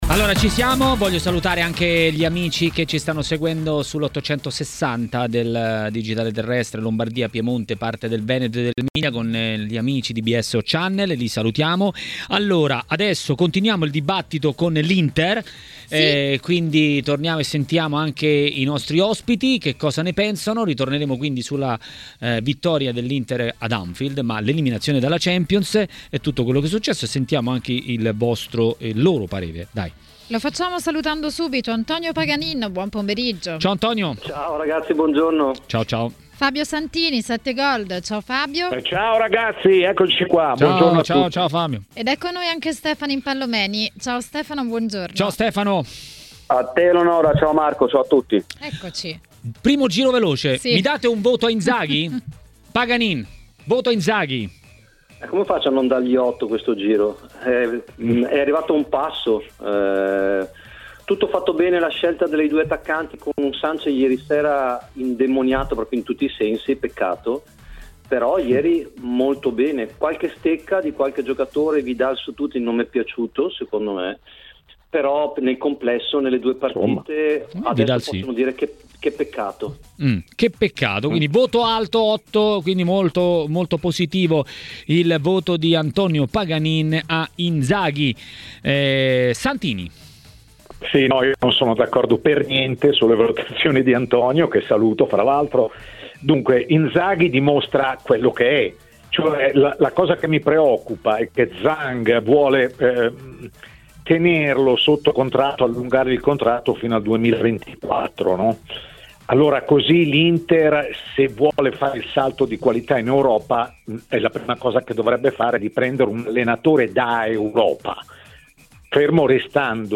Per parlare di tanti temi è stato a TMW Radio, durante Maracanà, l'ex calciatore Antonio Paganin.